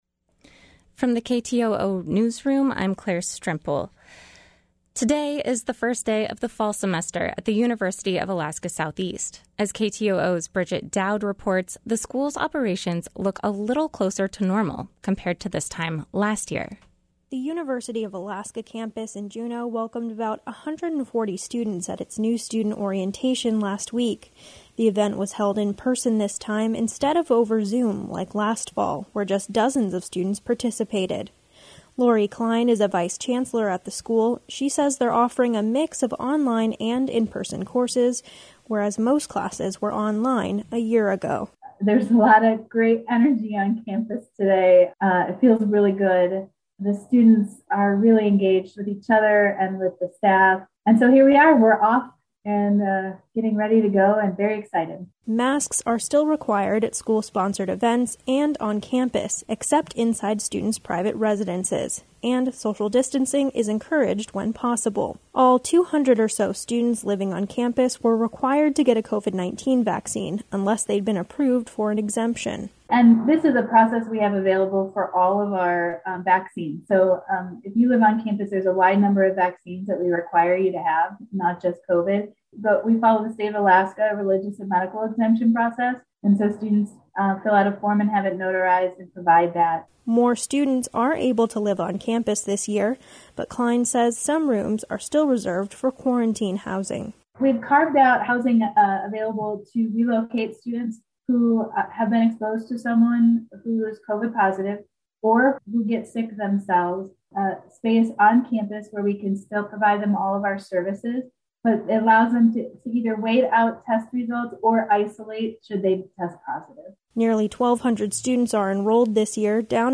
Newscast - Monday, Aug. 23, 2021